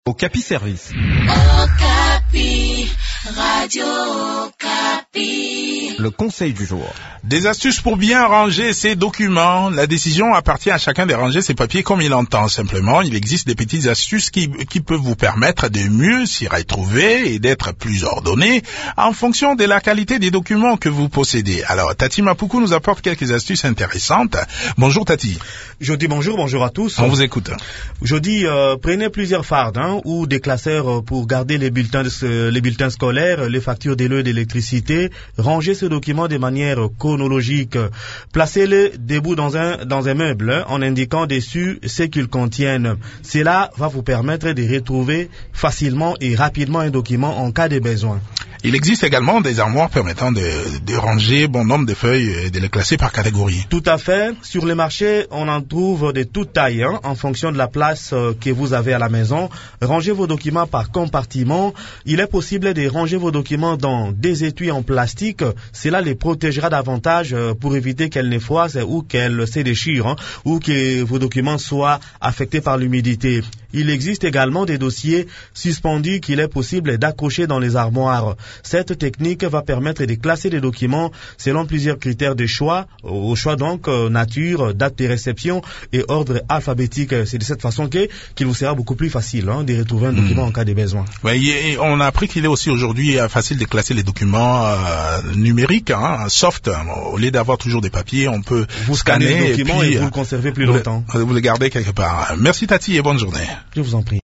Découvrez ces astuces dans cette chronique